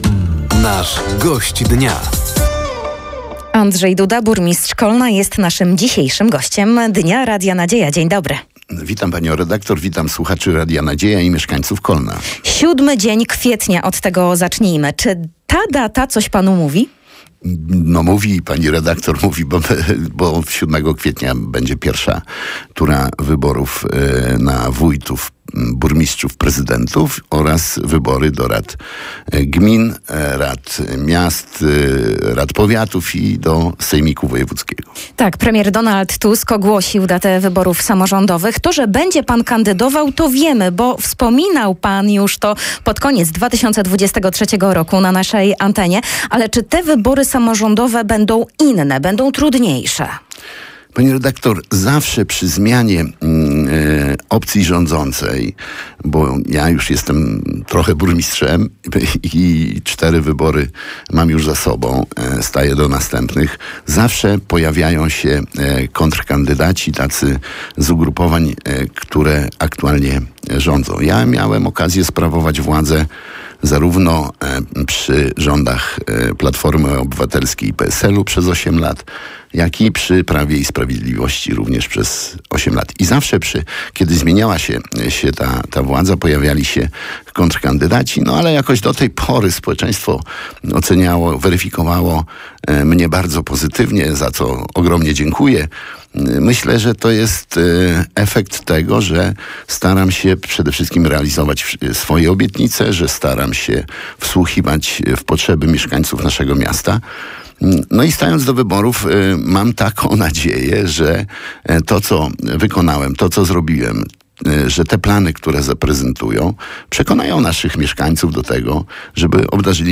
Gościem Dnia Radia Nadzieja był Andrzej Duda, Burmistrz Kolna. Rozmowa dotyczyła nadchodzących wyborów samorządowych, które odbędą się 7 kwietnia. Burmistrz Kolna wyjaśniał też, z czego wynikają obniżki cen energii cieplnej dla mieszkańców i przedsiębiorców w mieście.